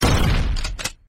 Shotgun free sound effects